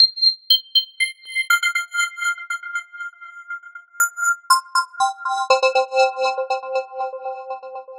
Night Chime 02.wav